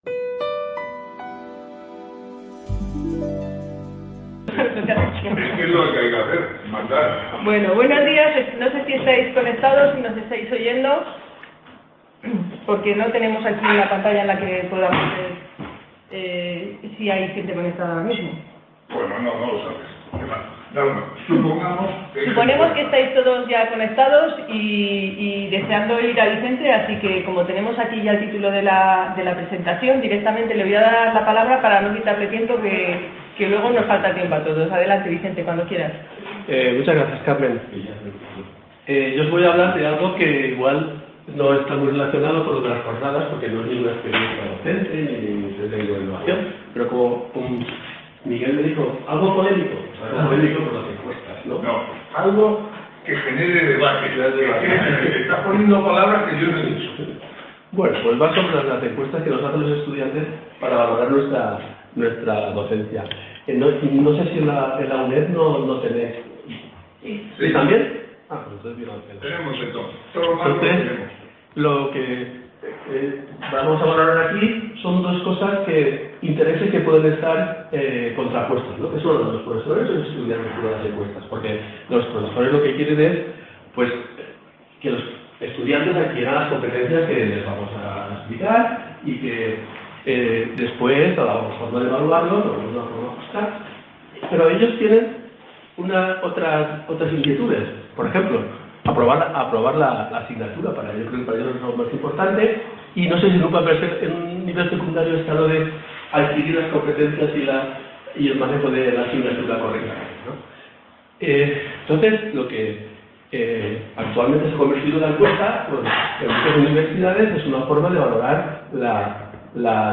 Ponencia S-1ª ¿La evaluación del profesorado por parte… | Repositorio Digital